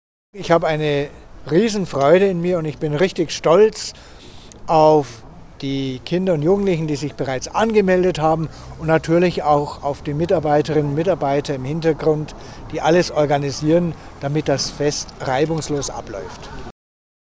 Dr. Gregor Maria Hanke, Bischof von Eichstätt